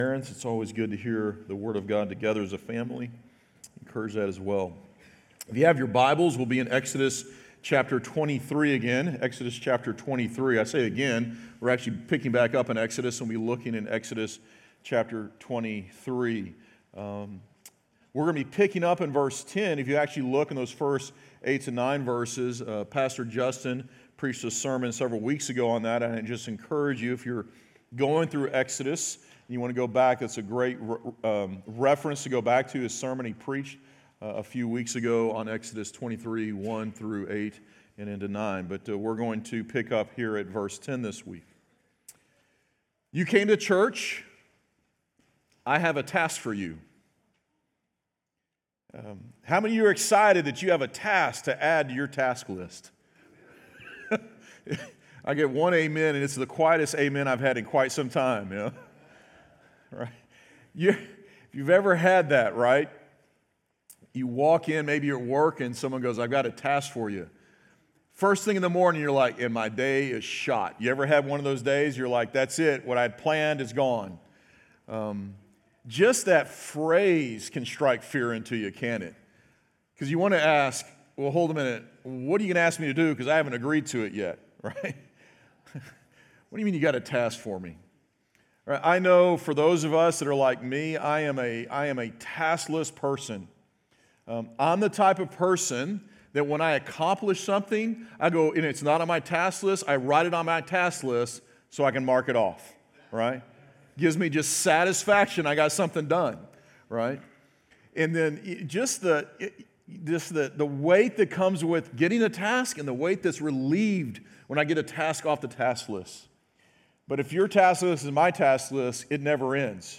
Sermon Audio Only